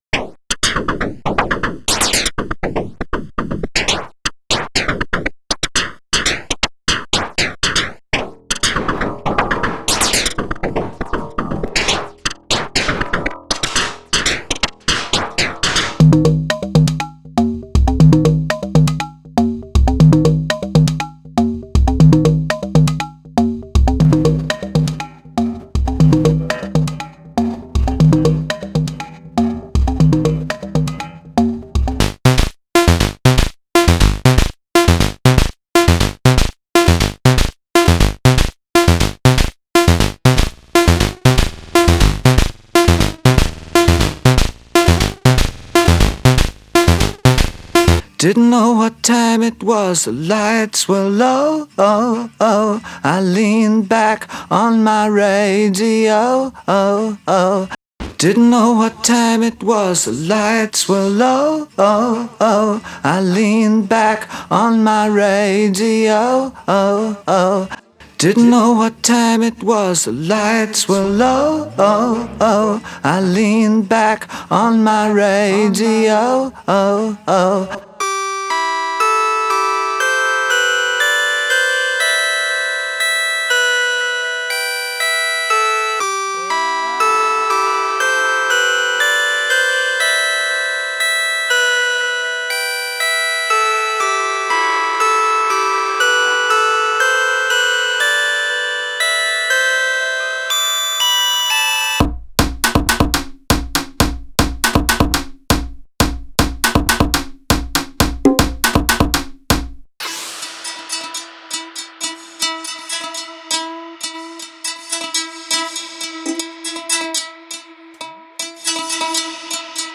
Wav Loops